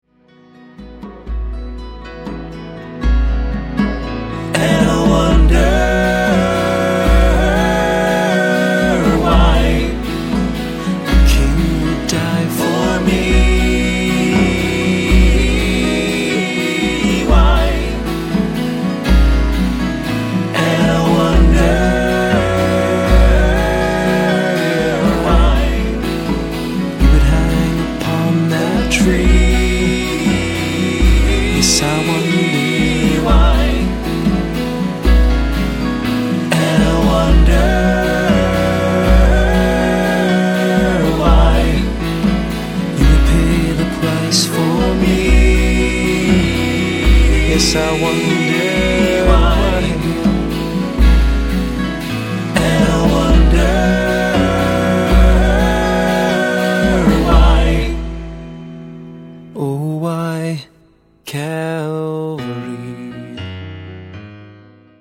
guitar driven